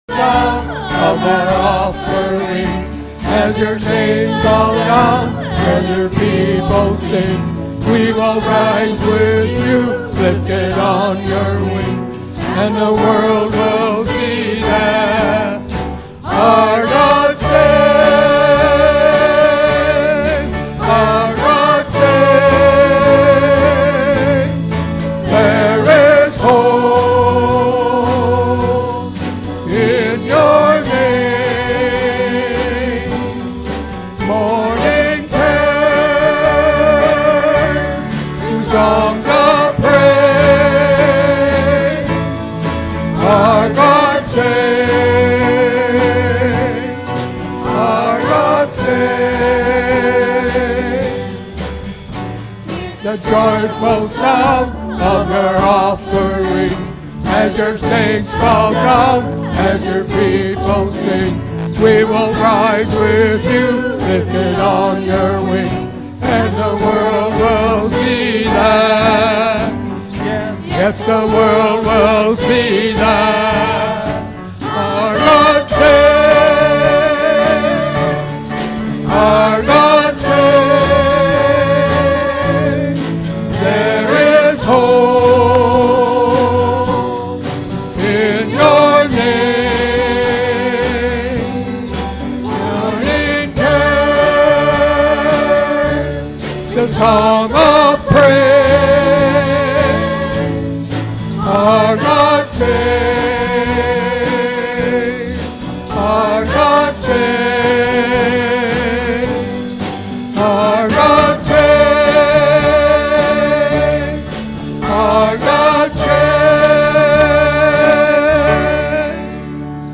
Worship-February-9-2025-Voice-Only.mp3